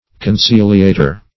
Conciliator \Con*cil"i*a`tor\, n.